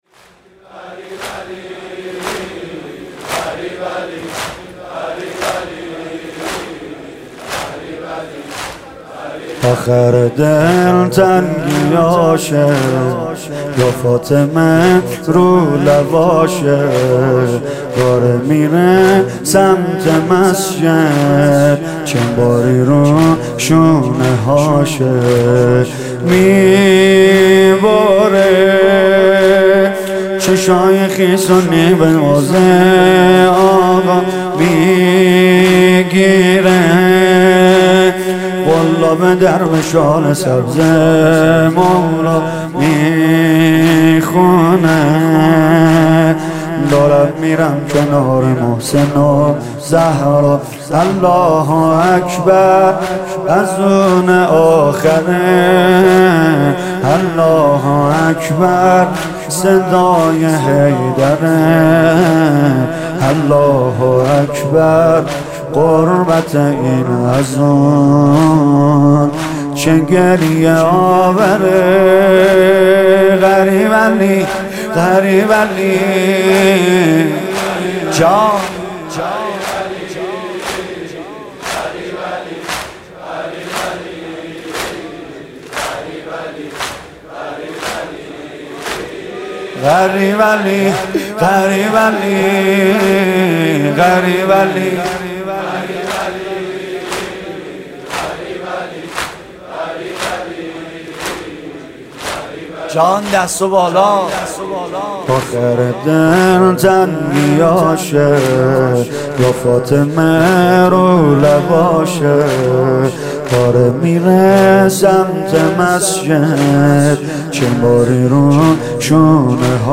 نوحه امام علی ضربت خوردن